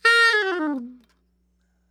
SOPRANO FALL
SOP SHRT A 4.wav